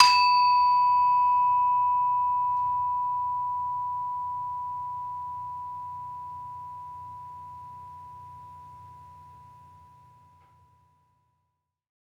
HSS-Gamelan-1
Saron-1-B4-f.wav